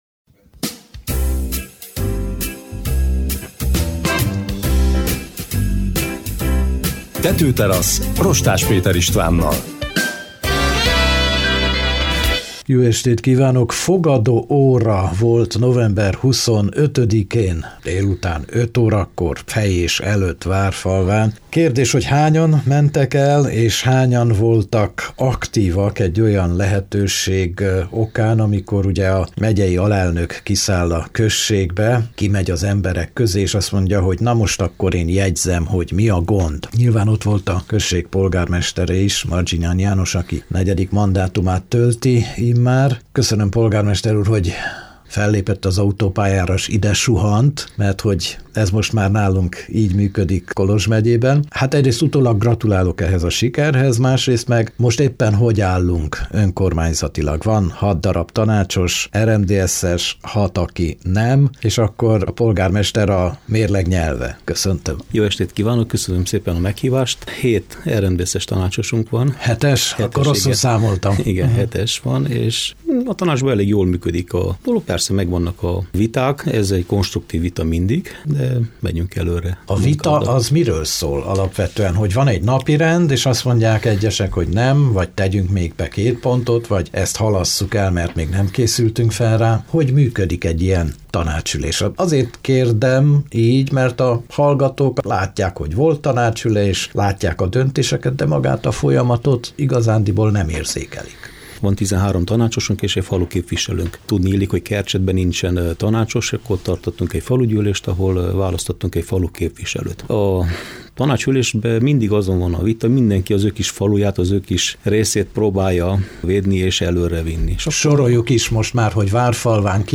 Mărginean János, a hat aranyosszéki települést magába foglaló Várfalva polgármestere a népességfogyás lassulásáról, fejlesztésekről, cssapatmunkáról, iskolaügyről, a még divatban levő bálokról is beszélt a Tetőteraszon.